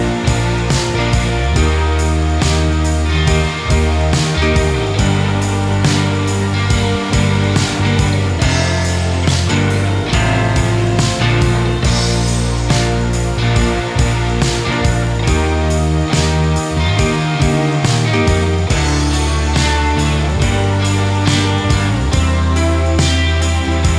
no Backing Vocals Pop (2000s) 4:20 Buy £1.50